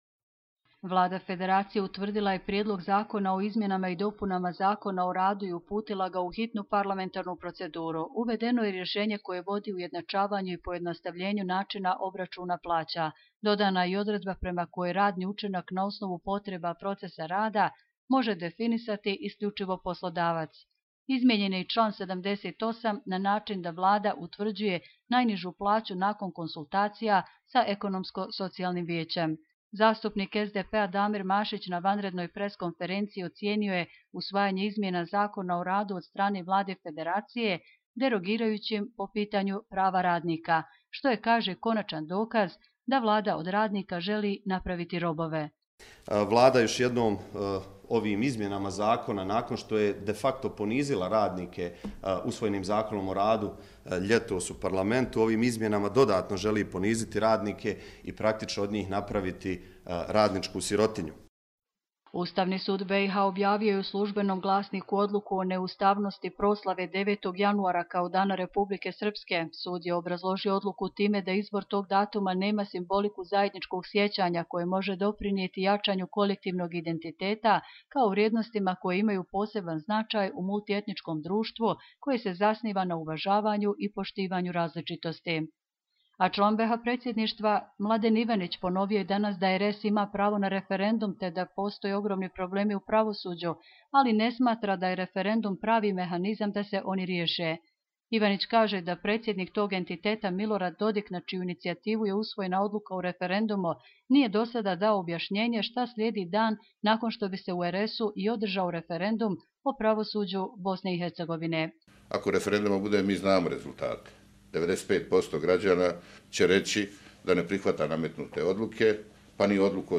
Audio izvještaji